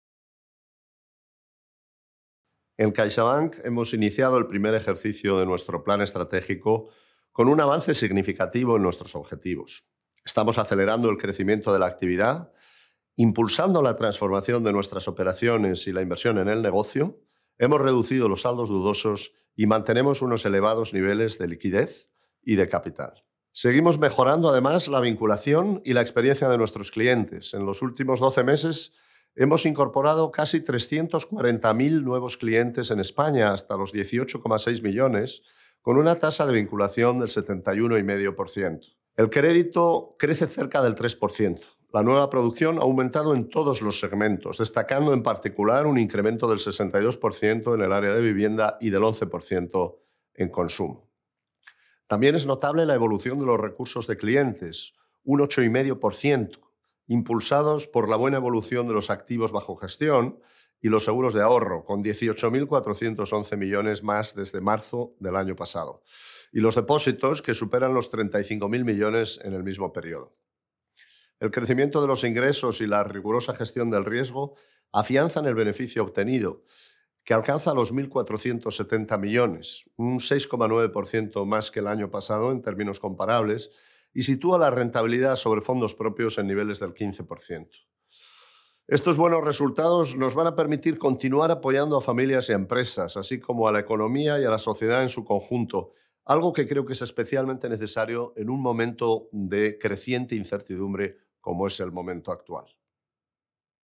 Audio del CEO de CaixaBank, Gonzalo Gortázar